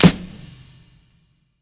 Clappington.wav